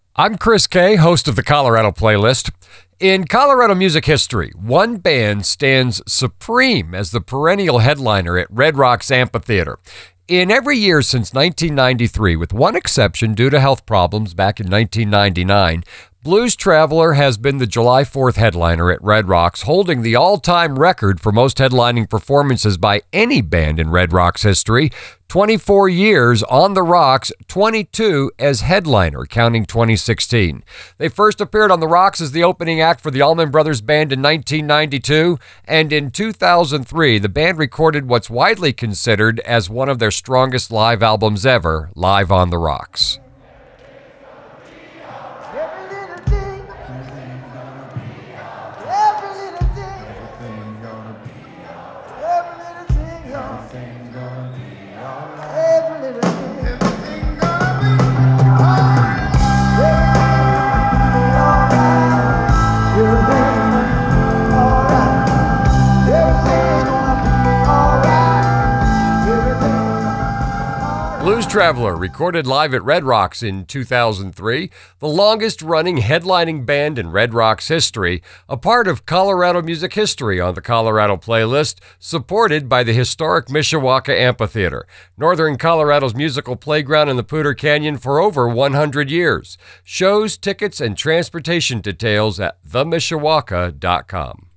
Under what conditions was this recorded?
cmh-blues-traveler-red-rocks-live-tag-mishawaka.wav